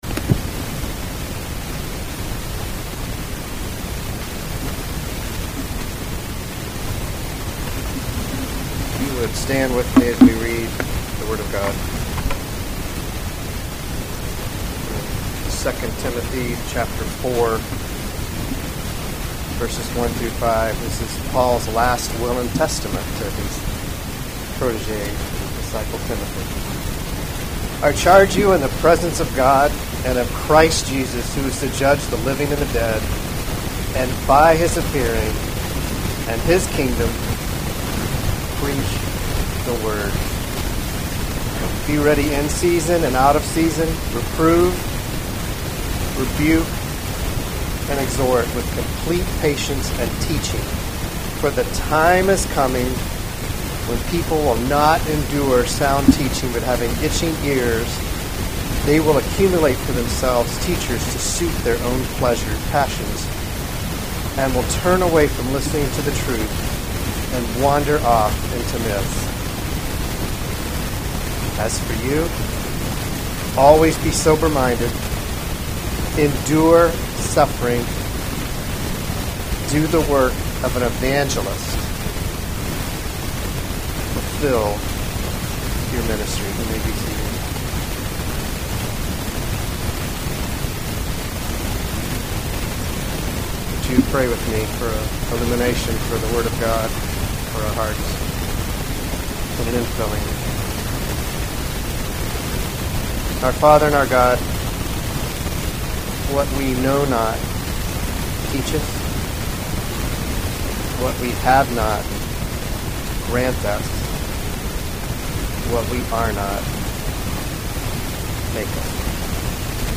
February 5, 2021 Guest Speaker Sermons series Presbytery Service Save/Download this sermon 2 Timothy 4:1-5 Other sermons from 2 Timothy Preach the Word 4:1 I charge you in the presence of […]